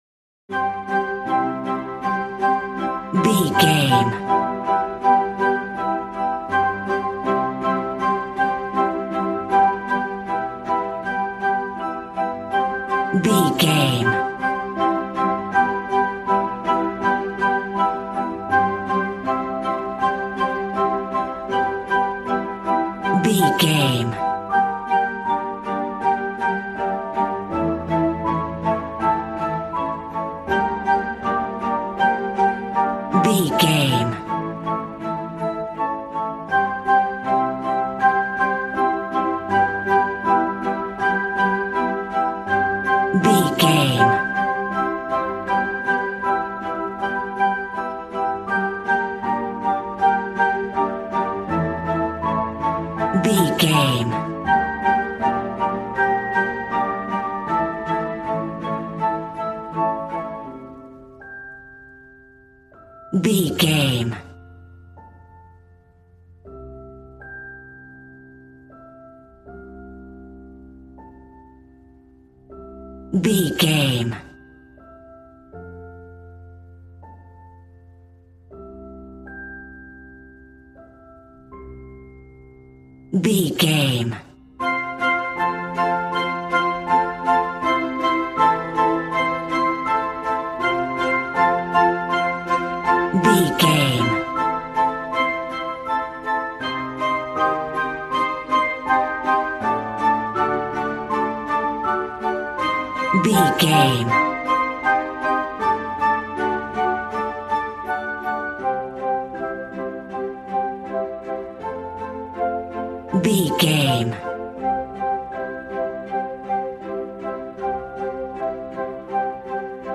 Regal and romantic, a classy piece of classical music.
Ionian/Major
strings
violin
brass